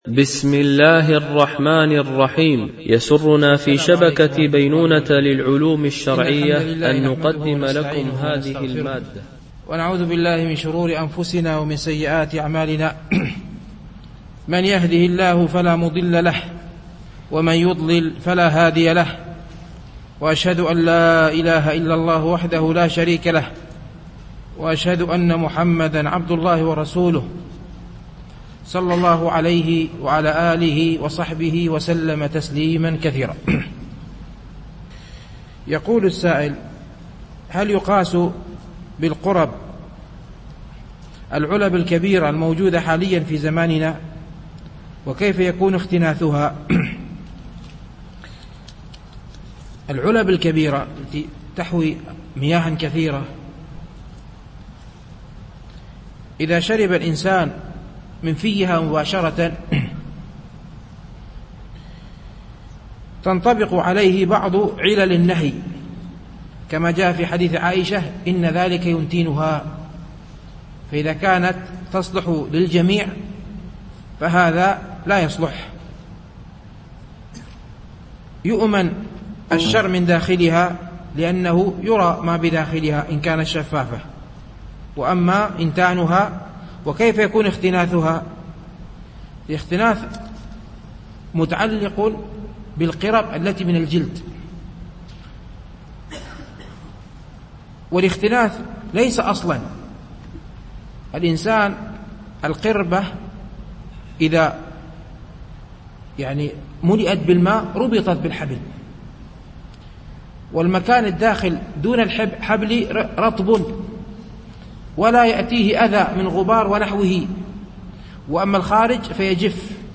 شرح رياض الصالحين - الدرس 209 ( الحديث 770 - 775)